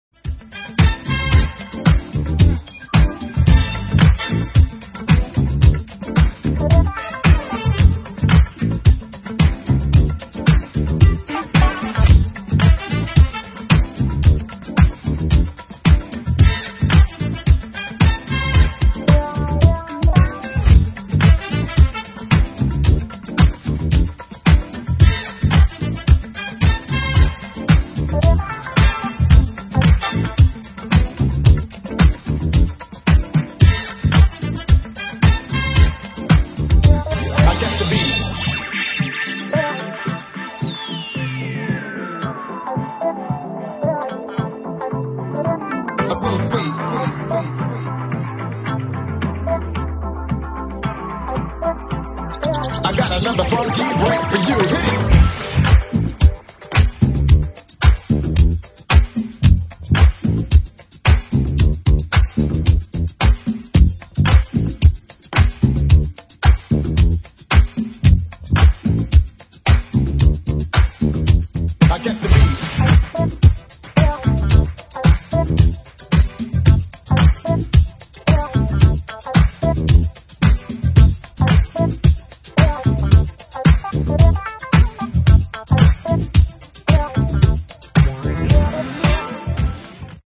Brazilian boogie